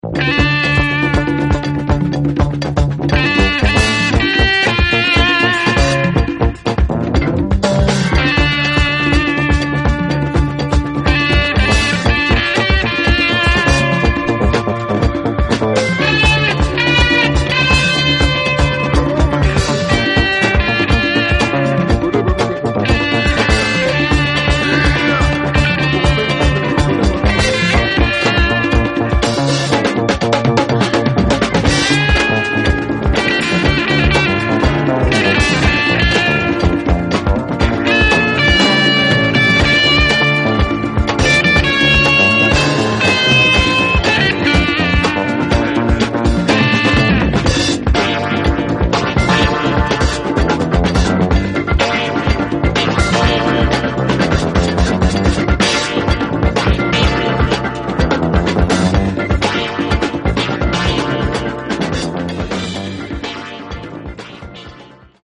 funk album
funk and soul